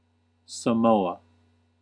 wymowa, pełna oficjalna nazwa: Niezależne Państwo Samoa) – państwo w Oceanii, obejmujące zachodnią część Wysp Samoa (pozostałe stanowią terytorium Stanów Zjednoczonych jako Samoa Amerykańskie).
En-us-Samoa.ogg.mp3